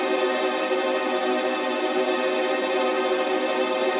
GS_TremString-Cdim.wav